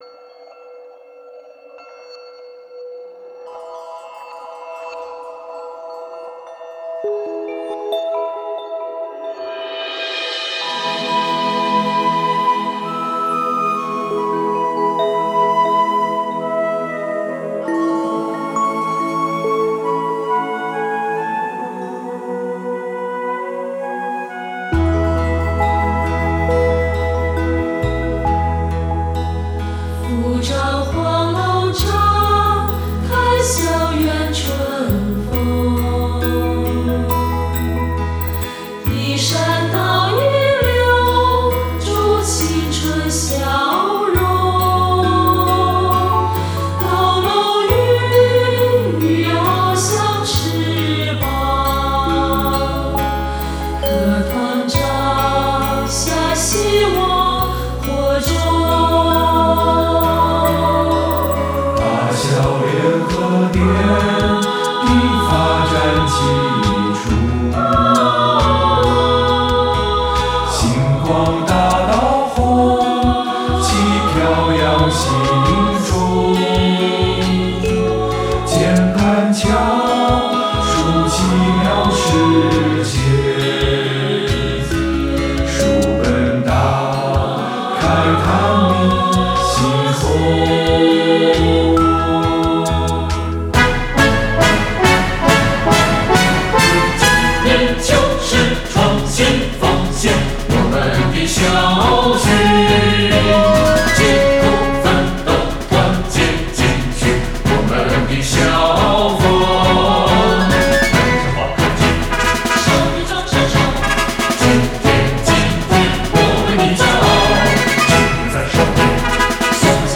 合唱版（带歌词）：